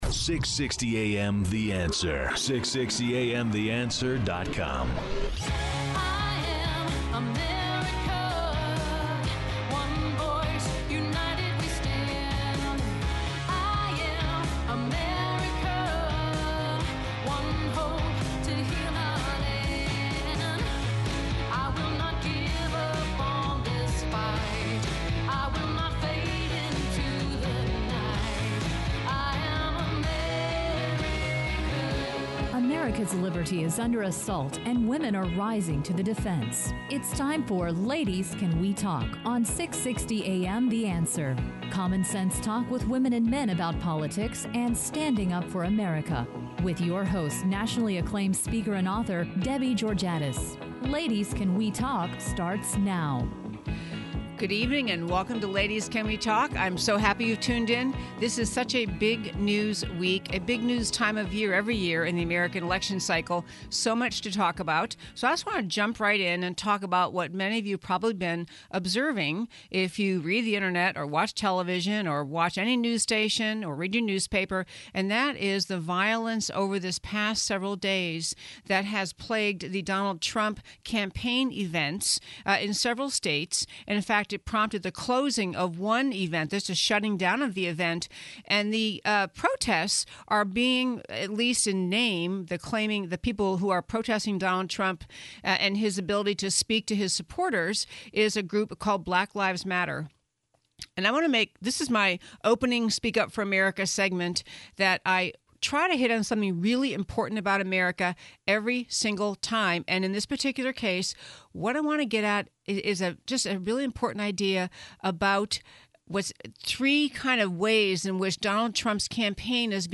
Trump’s Opposition and Core Principles; Interview with Congressman Jeb Hensarling on the Article One Project
Congressman Jeb Hensarling calls in to talk about he and Senator Mike Lee and their Article One Project—a critical piece of returning the nation to constitutional governance.